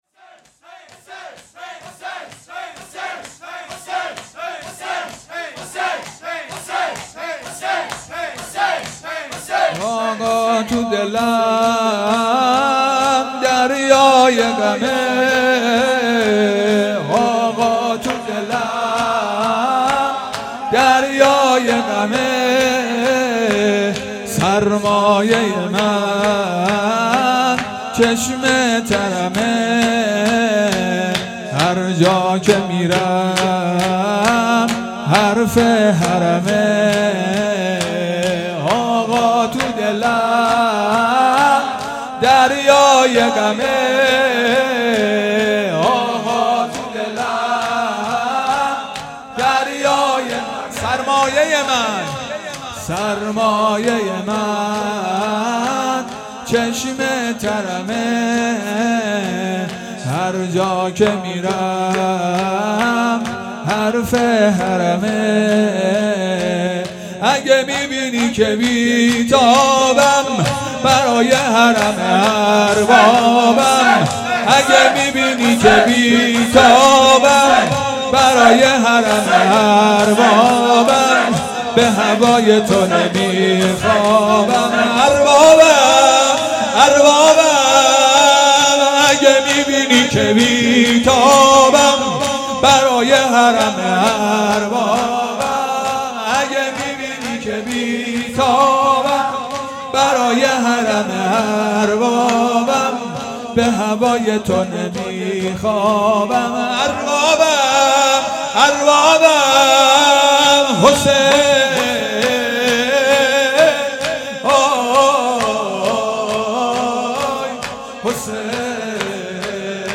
شب چهارم محرم الحرام 1441